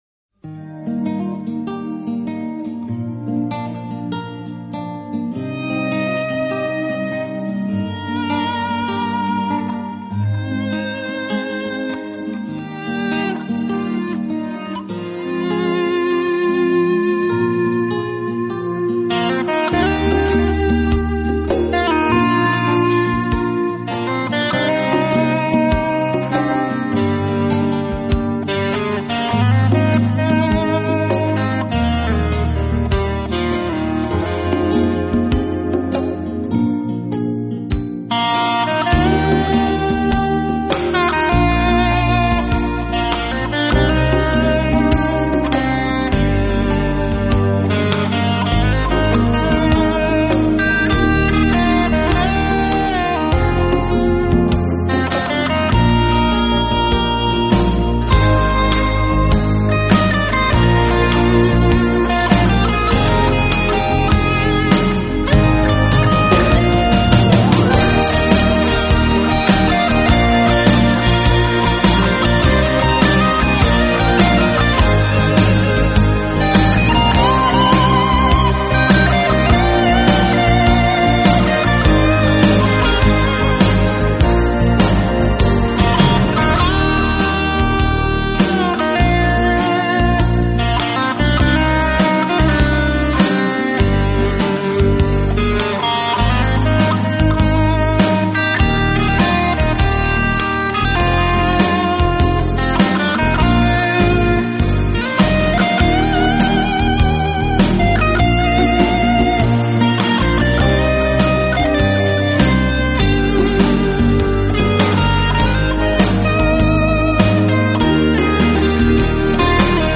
【新世纪音乐】
所属语言：纯音乐
音乐家，电子键盘手
表现出来的宁静和平和感染着听众，从此开始他的顺利音乐路线。